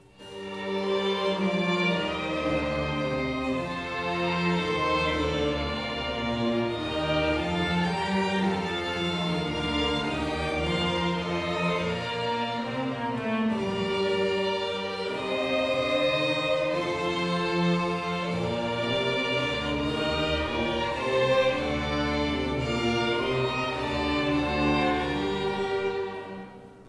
Kostproben vom Weihnachtskonzert 2024:
Menuet Streicherorchester – Mittel- und Oberstufe
Weihnachtskonzert2024_Menuet_Streicherorchester_MittelOberstufe.mp3